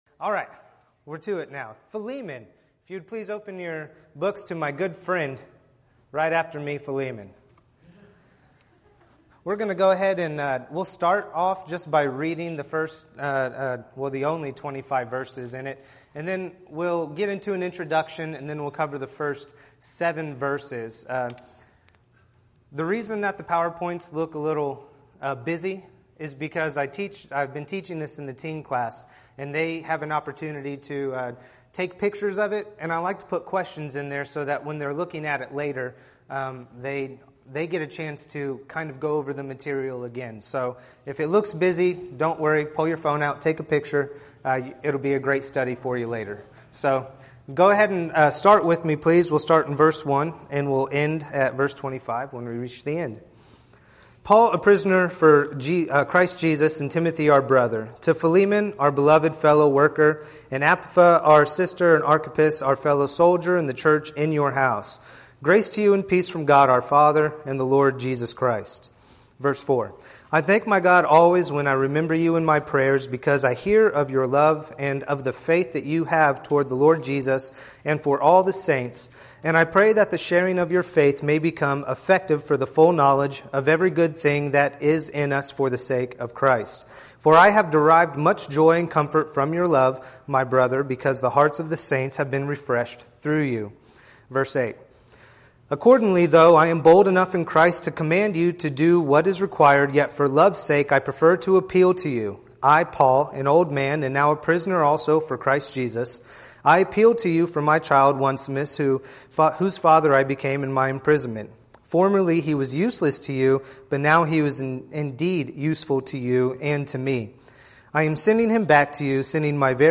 Podcasts Videos Series Sermons Philemon